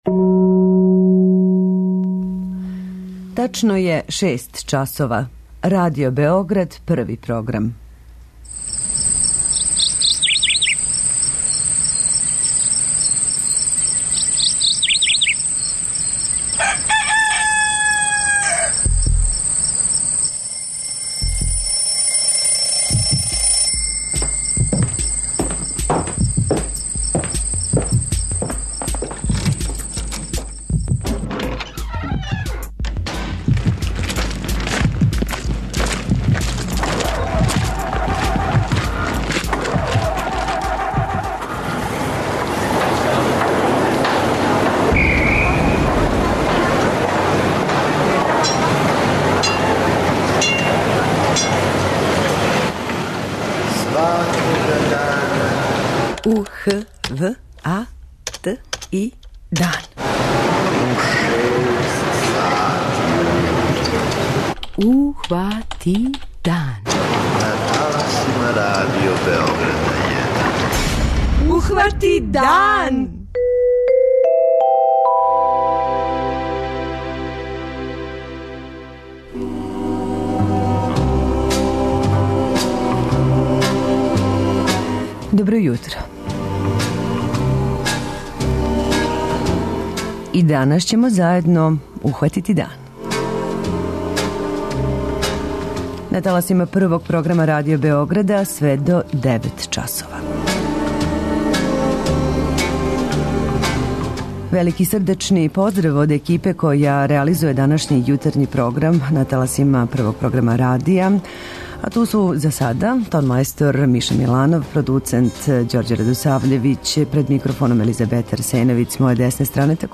Питање јутра - контакт-програм са слушаоцима: колико грађани могу да утичу на доношење одлука о средини у којој живе, на изглед, функционалност и здравље својих градова и села?